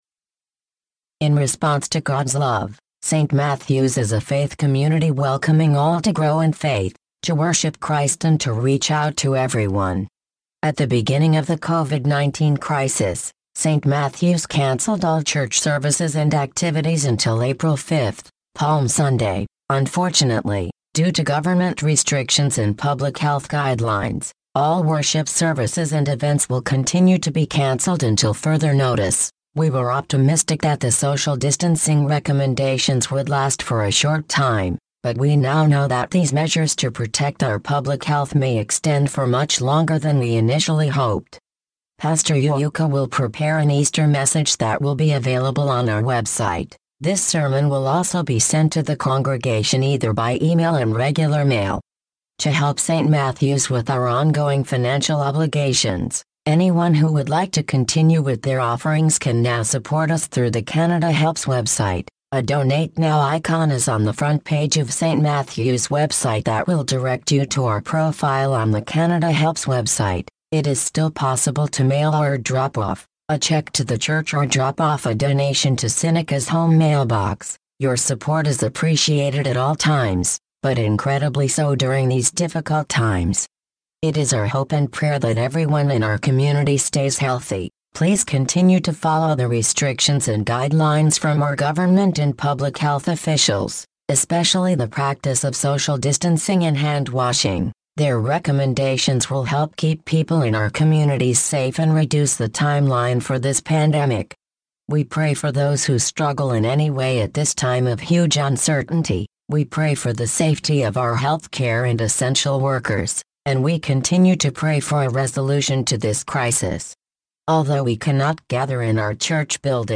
An audio version (text reader) can be heard HERE .